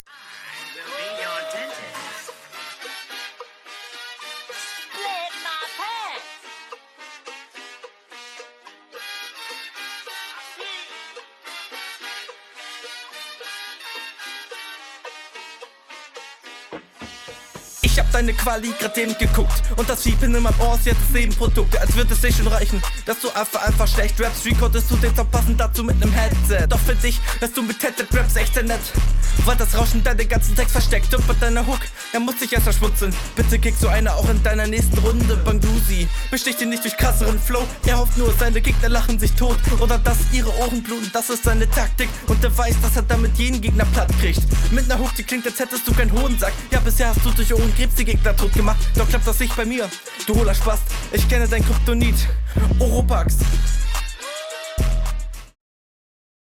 Schnelles Battle Format